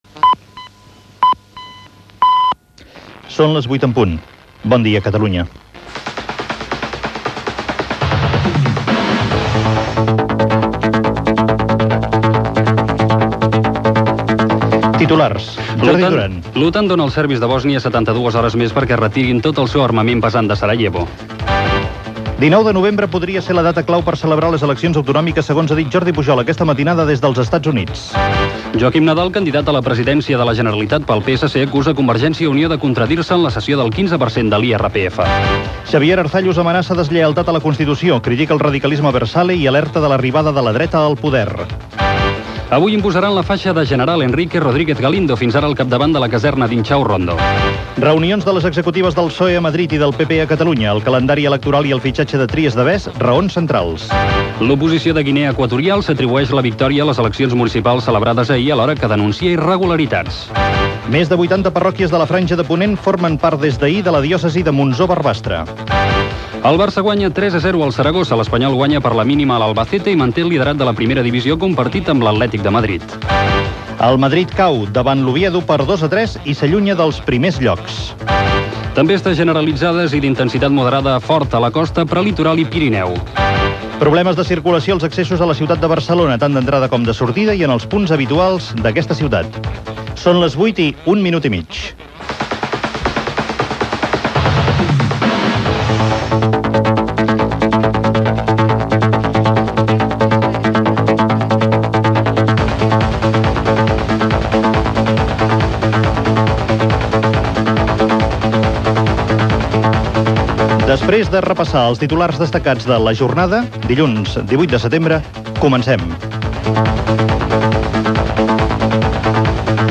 Primera edició del programa a COM Ràdio. Senyals horaris,titulars informatius de la jornada, esports, el temps i estat del trànsit . Informació sobre Bòsnia
Info-entreteniment